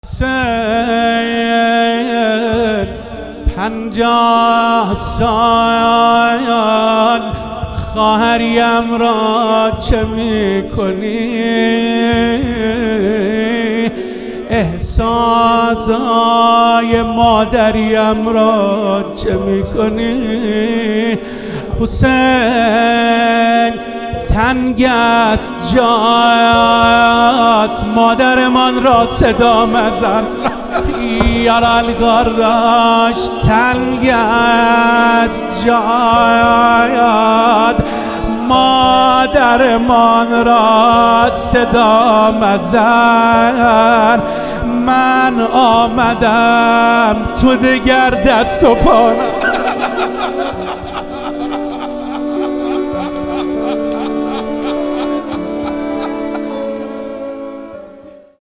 روضه گودال